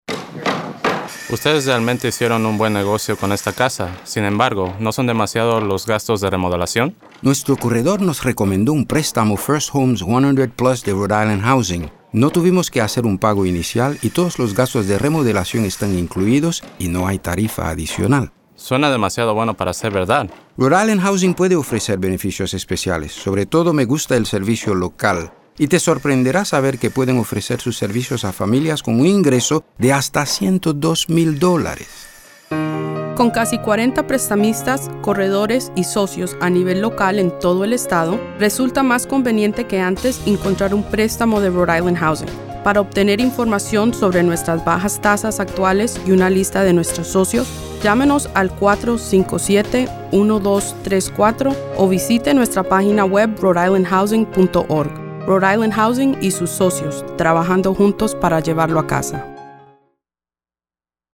Radio Ad Samples